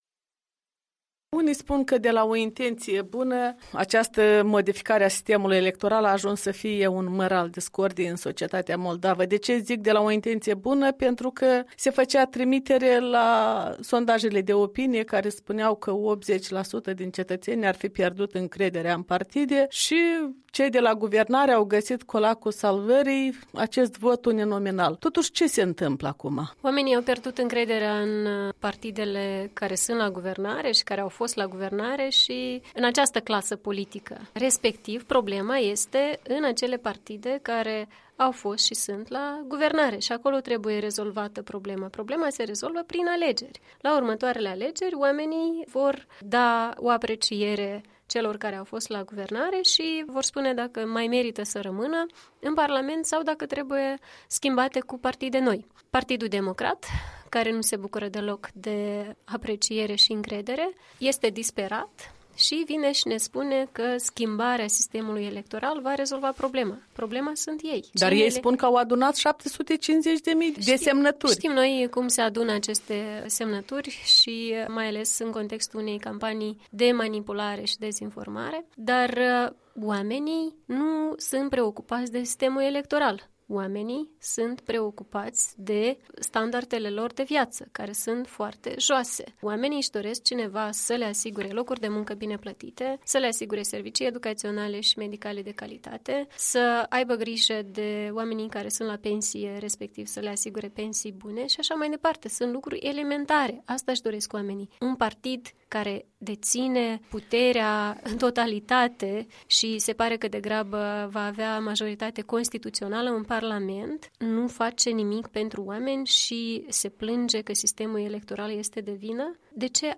Un interviu cu lidera PAS despre eforturile partidelor de la guvernare de a introduce votul uninominal.